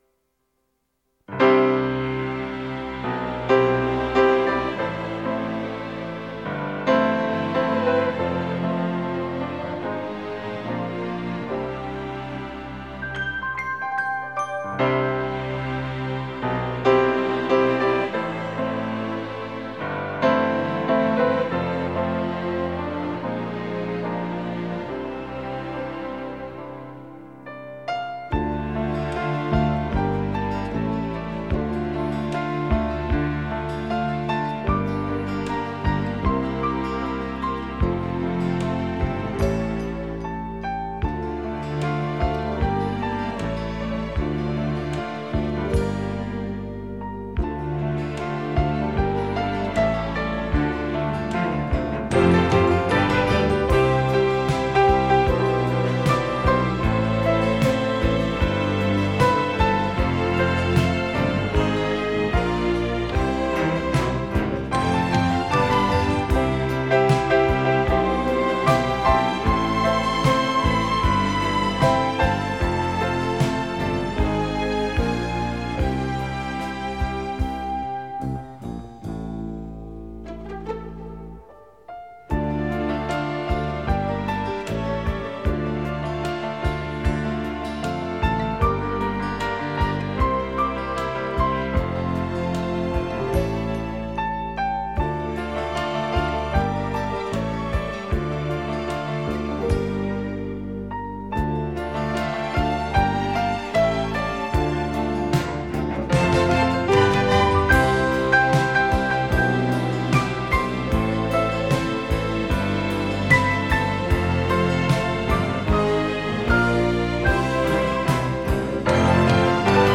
3周前 纯音乐 8